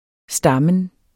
Udtale [ ˈsdɑmən ]